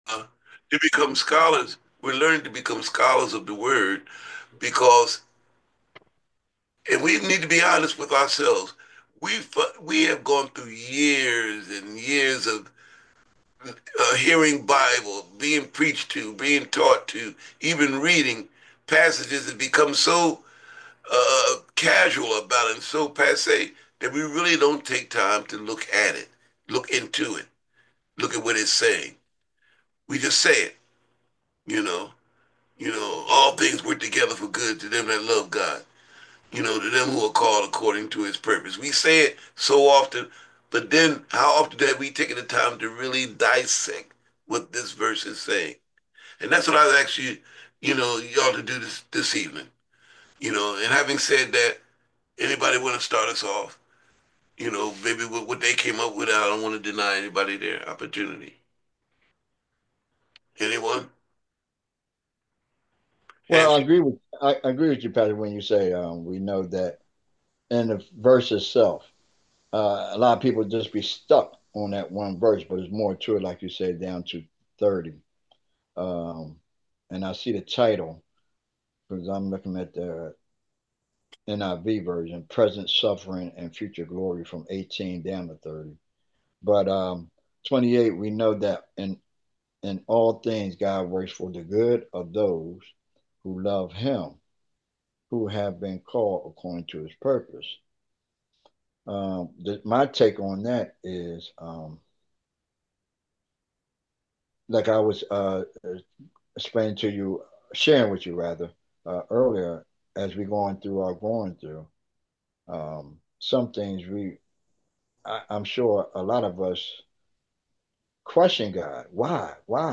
BIBLE STUDY: WHAT DOES ROMANS 8:28 MEAN?